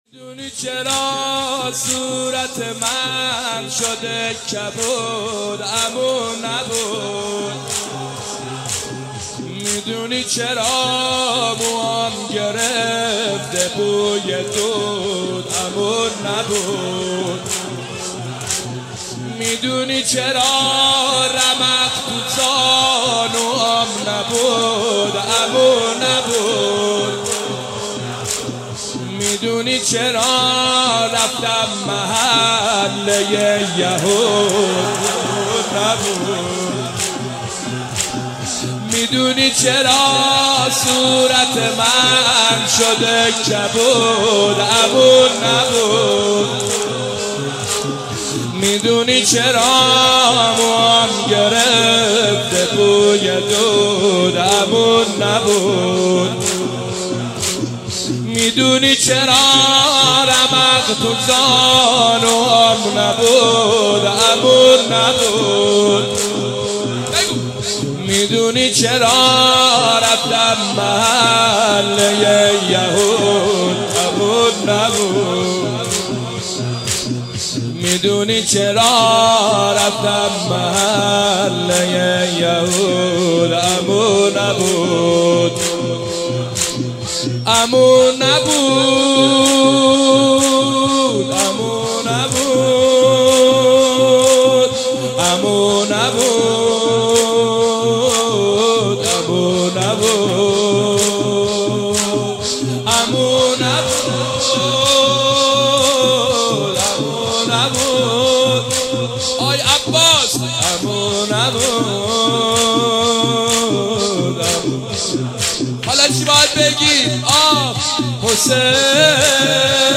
میدونی چرا صورت من شده کبود | زمینه | حضرت رقیه سلام الله علیها
جلسه ی هفتگی